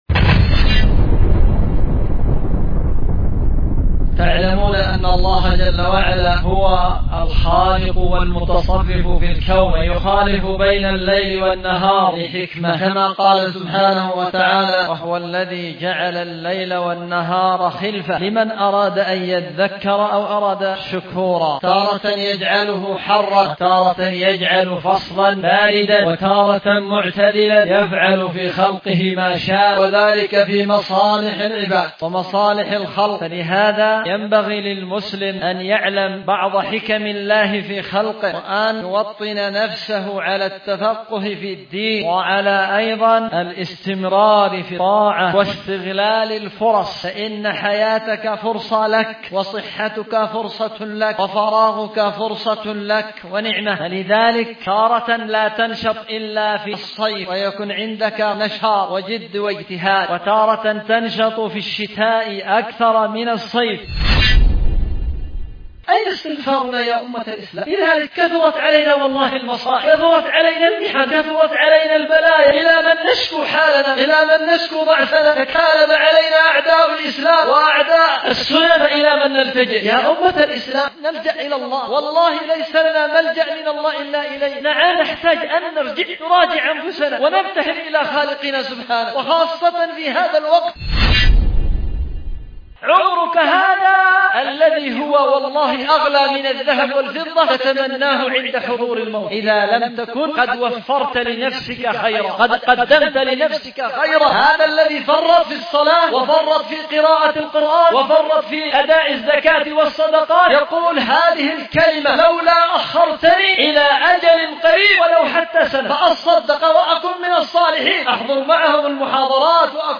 محاضرة
ألقاها في جامع الخير بصنعاء